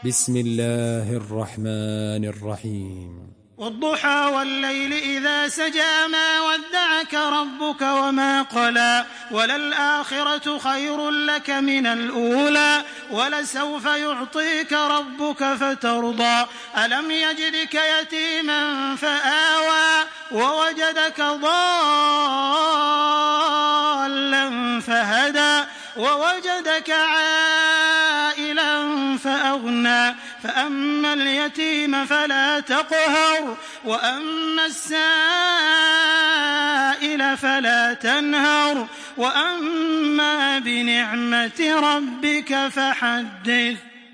تحميل سورة الضحى بصوت تراويح الحرم المكي 1426
مرتل